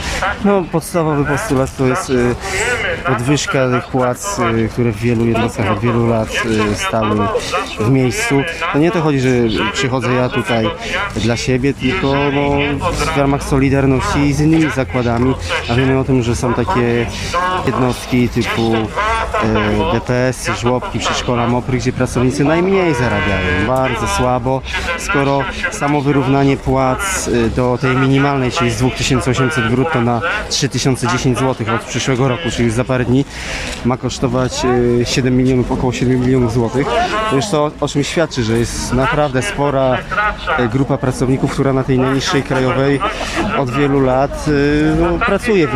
Dzisiaj o 15 przed urzędem Miasta w Szczecinie odbył się protest NSZZ ,,Solidarność” pracujących w budżetówce.